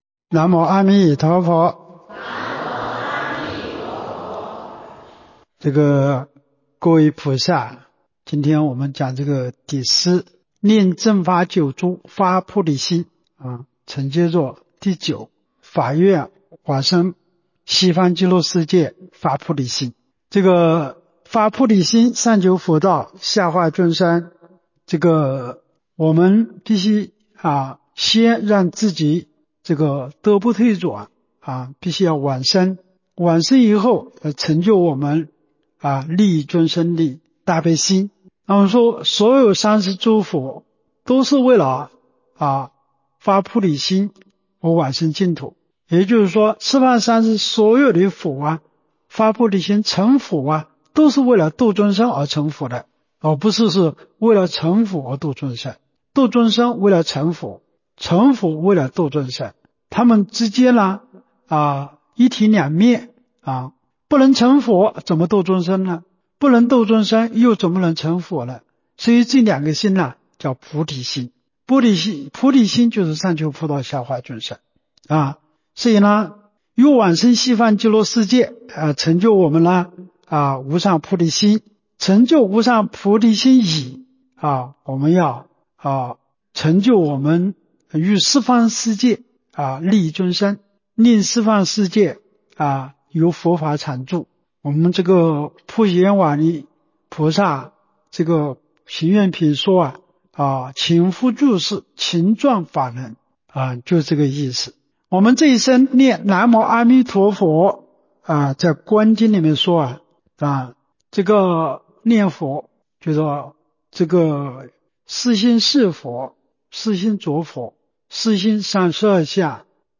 彭泽集福寺佛七开示